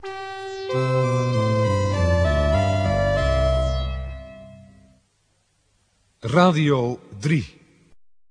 die pingel zijn de eerste noten